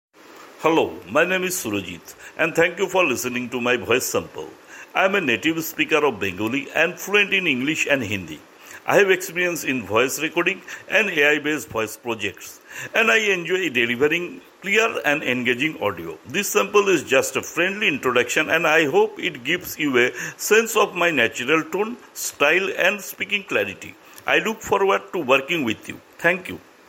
English Commercial Voiceover (Indian Accent)
I work from a broadcast-quality home studio, offering clean, edited audio in MP3/WAV formats, fast turnaround, and flexible tone delivery — from confident and professional to warm, friendly, and emotional.